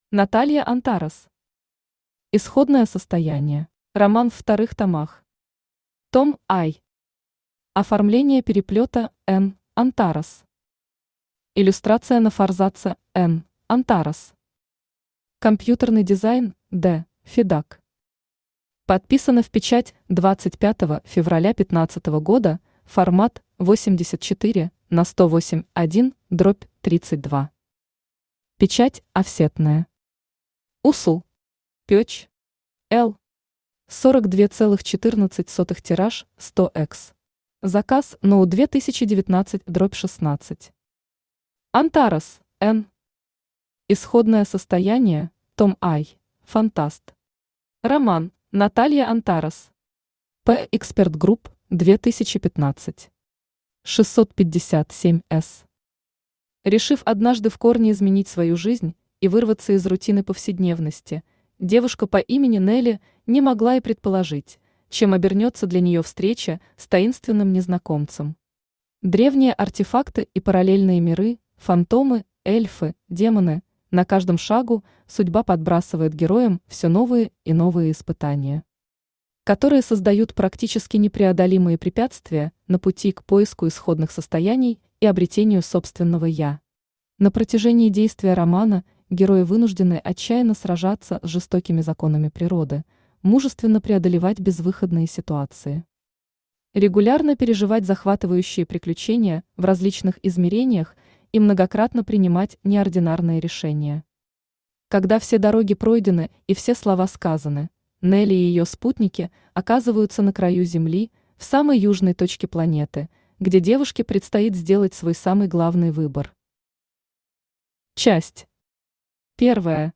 Аудиокнига Исходное состояние | Библиотека аудиокниг
Aудиокнига Исходное состояние Автор Наталья Антарес Читает аудиокнигу Авточтец ЛитРес.